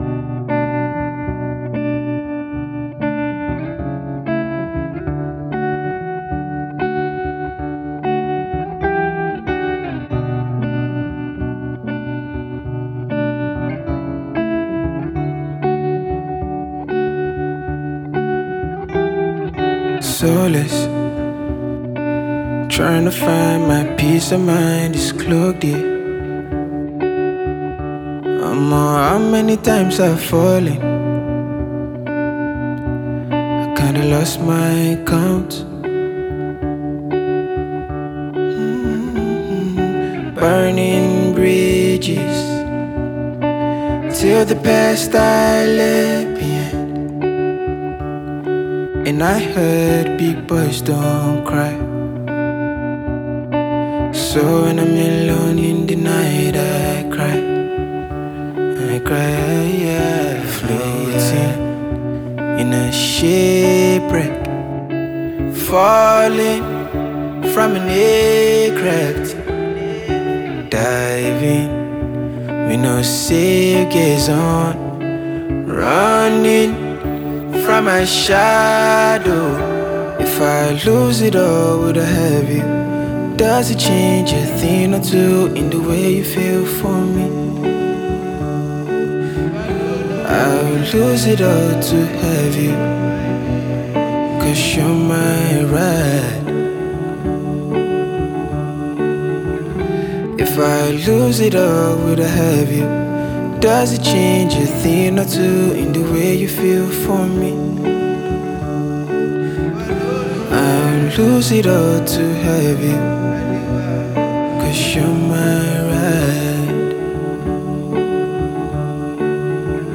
Fast-rising Nigerian singer
The production is minimal yet powerful
alternative and Afro-soul music